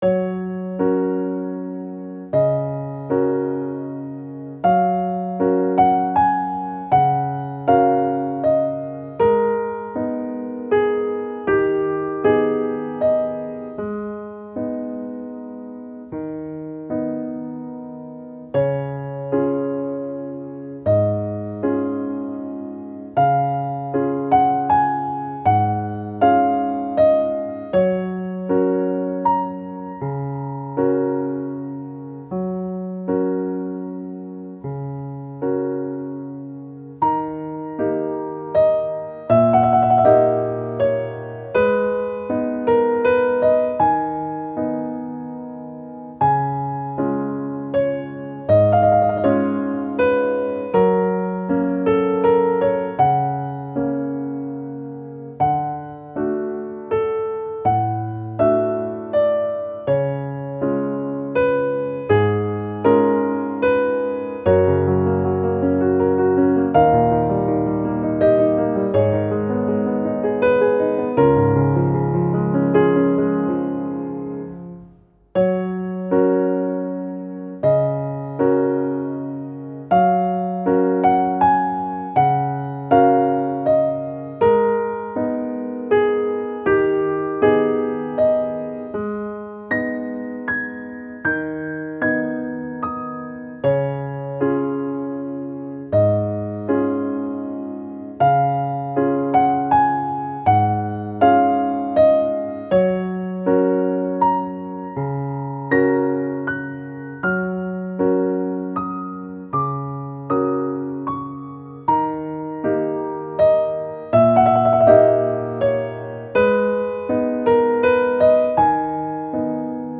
• 暗めのしっとりしたピアノ曲のフリー音源を公開しています。
ogg(R) - スロウ まったり 日常